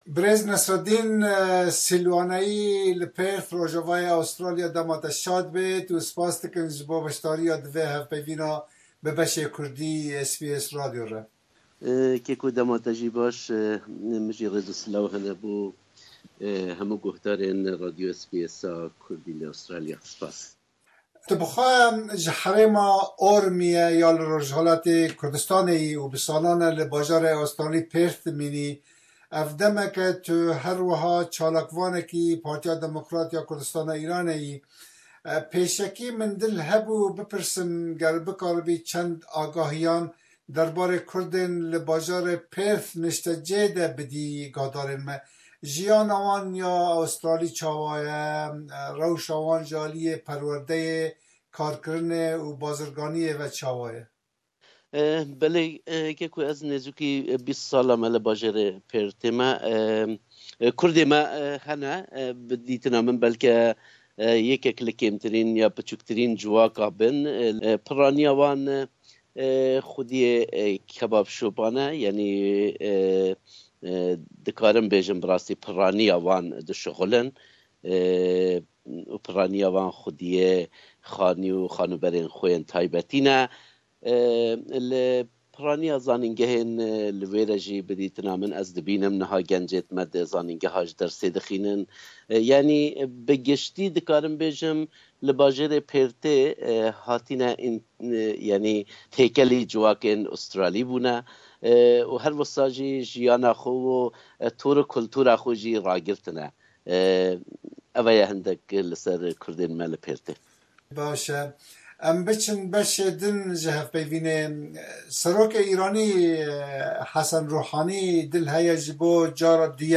Kurdên rojhilatê Kurdistanê ji hukûmeta Komara Islamî razî ne? Guh bide hevpeyvînê.